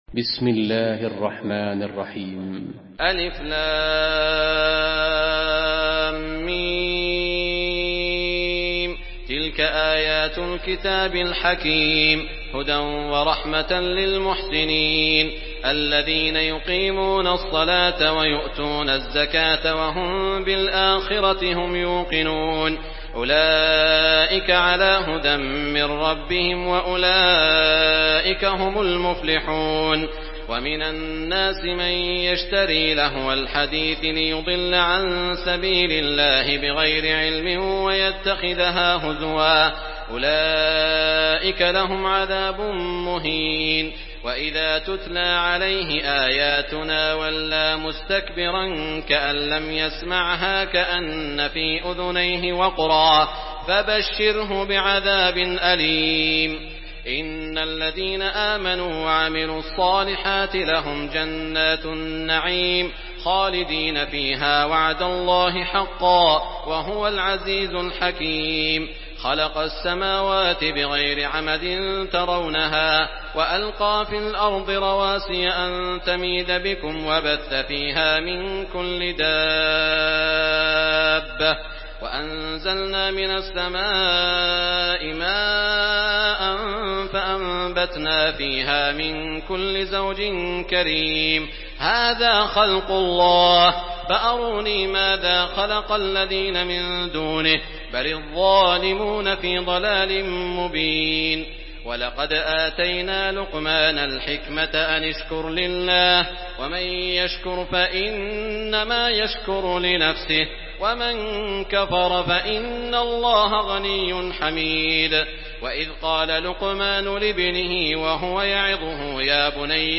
Surah لقمان MP3 by سعود الشريم in حفص عن عاصم narration.
مرتل